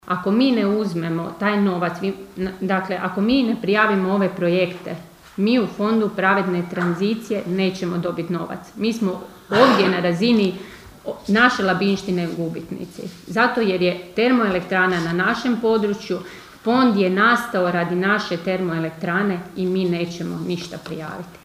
Sjednica Općina Kršan
Dodatno je pojasnila načelnica Ana Vuksan: (